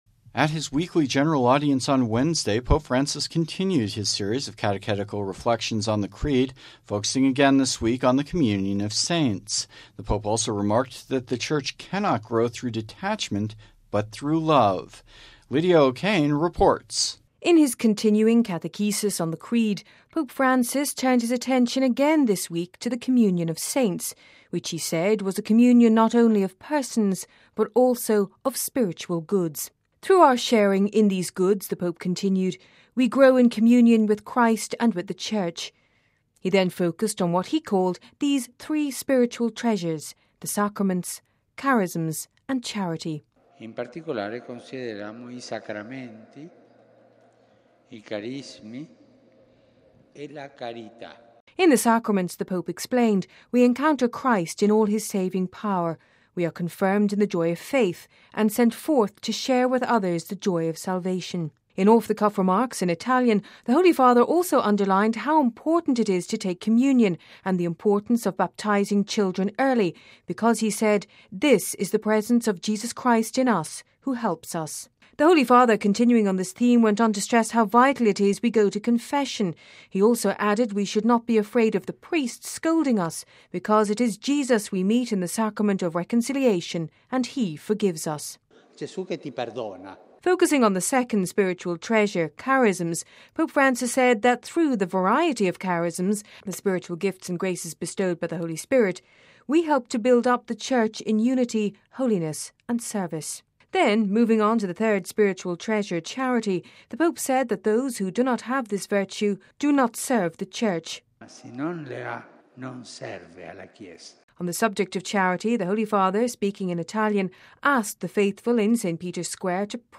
(Vatican Radio) At his weekly General Audience on Wednesday, Pope Francis continued his series of catechetical reflections on the Creed, focusing again this week on the Communion of Saints. The Pope also remarked that the Church cannot grow through detachment, but through love.